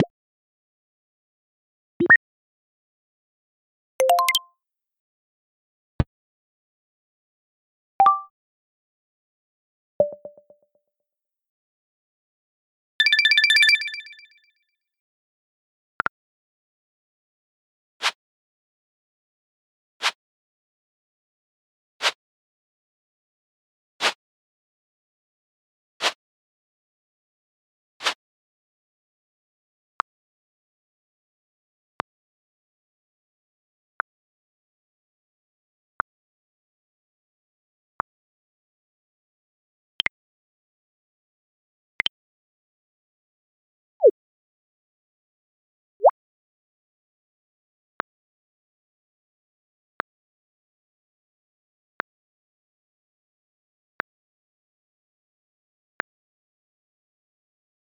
It provides a simple API to play sounds for various UI interactions like button clicks, notifications, warnings, errors, and more.
One more thing I noticed about SND is that it uses something called audio sprites (like image stripes) to optimize the loading and playback of sounds.
a single audio file (sprite) and plays specific segments of that file as needed.